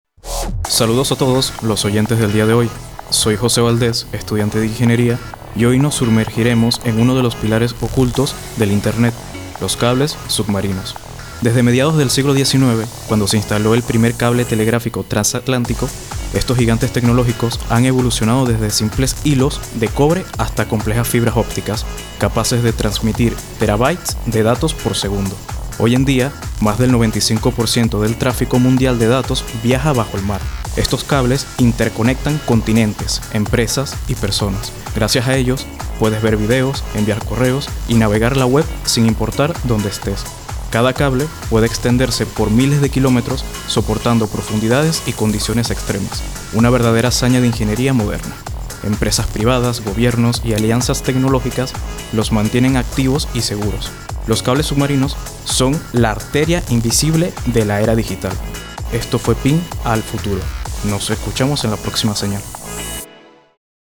Estudiantes de la carrera de Ingeniería de Sistemas